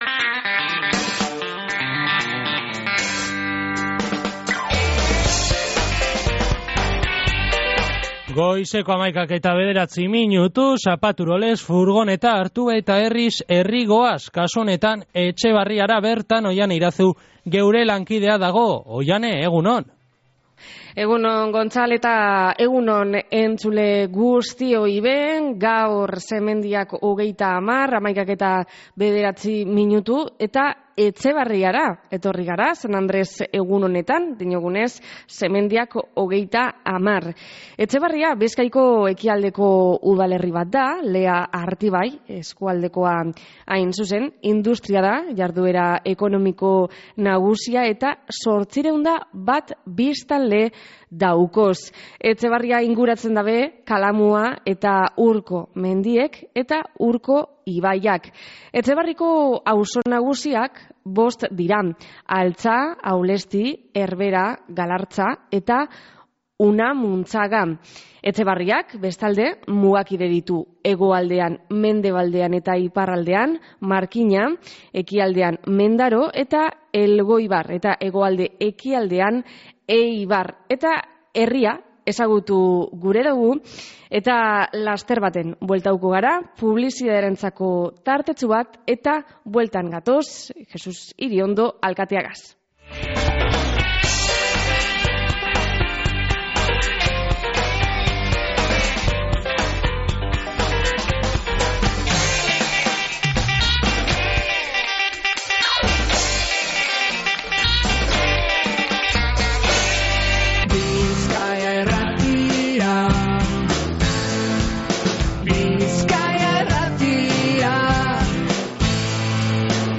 Herriz Herri Etxebarriatik San Andres egunean